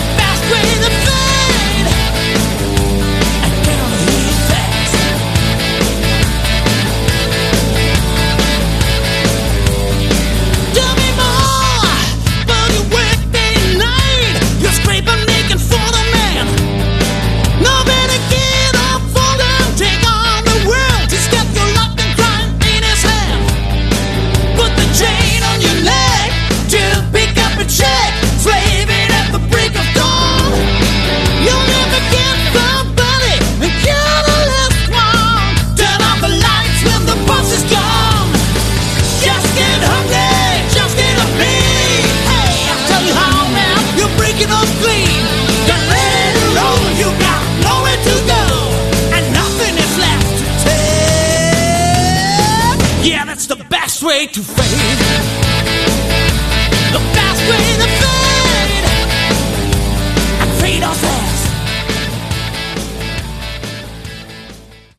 Category: Hard Rock
Straight ahead hard rock, a few cool riffs and hooks.